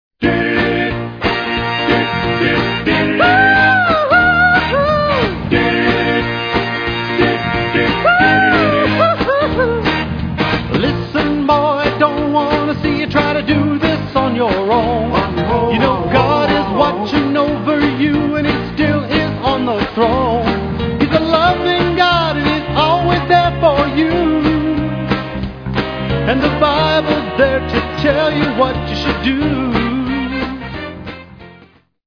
Christian lyrics written to the tune of popular songs
You will love the upbeat music and fun Christian message.